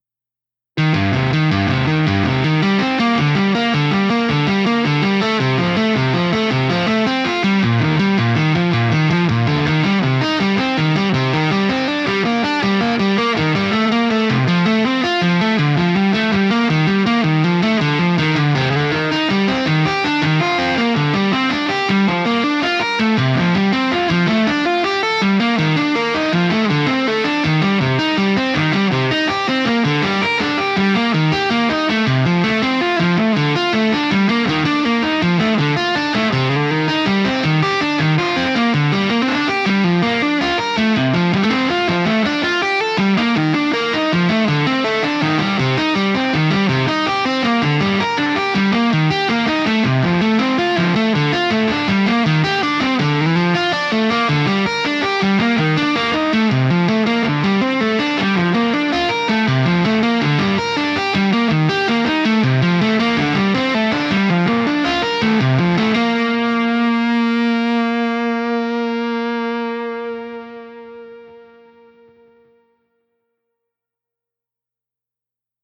Chapman Stick
VideoStick